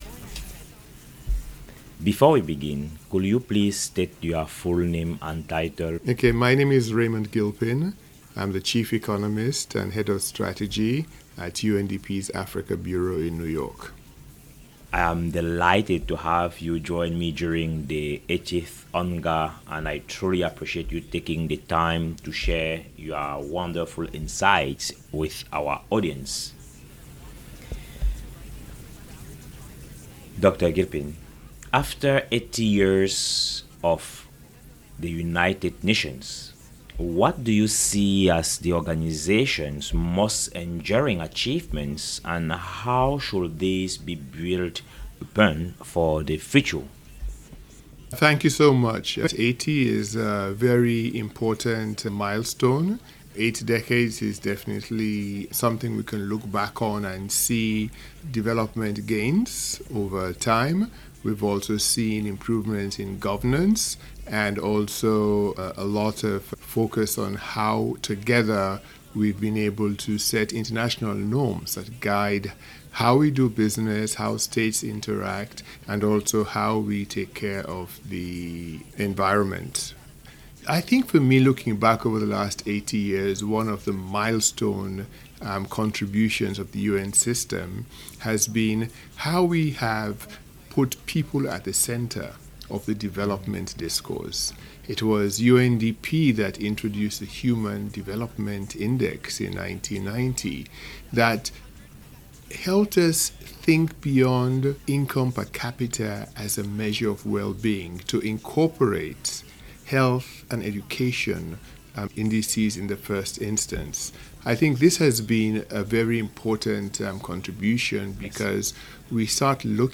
Editor's note: This audio recording is part of the UNGA 2025 special episode of the Foresight Africa podcast .